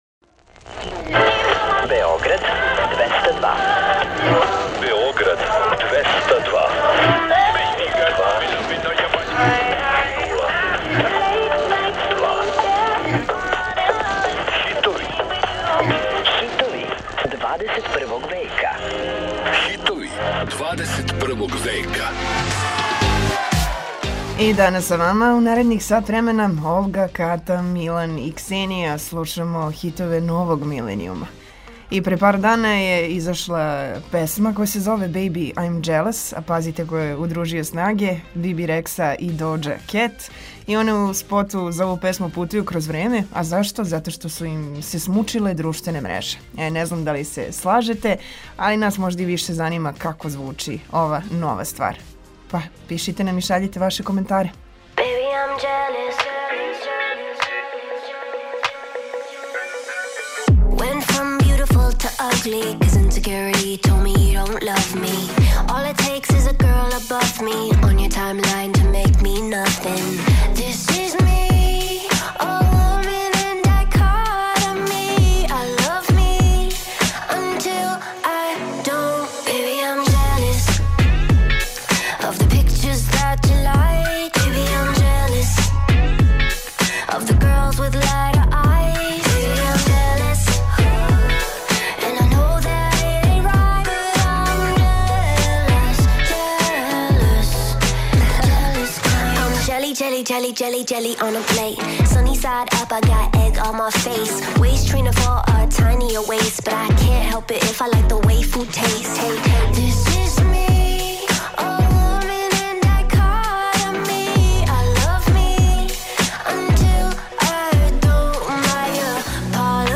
Чућете песме које заузимају сам врх светских топ листа.